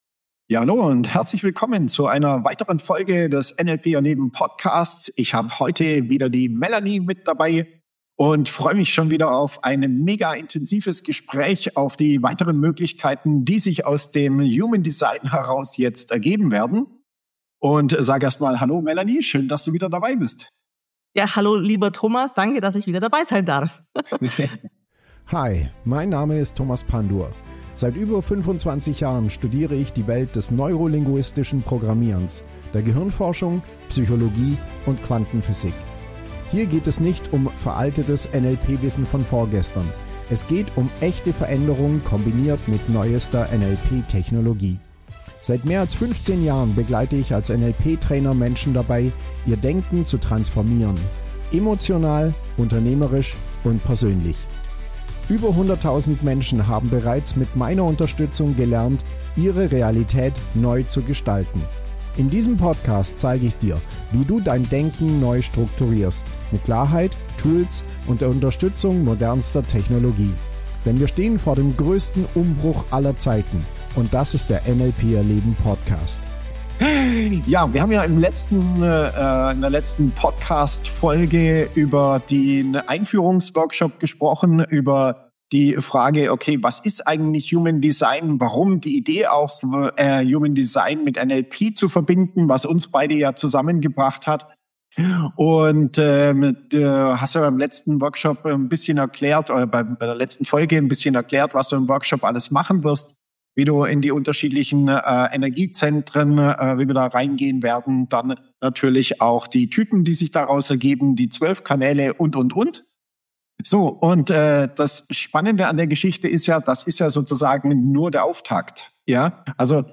Beschreibung vor 4 Tagen Warum fühlen sich manche Entscheidungen sofort richtig an und andere ziehen dir Energie? Genau darüber spreche ich in diesem Interview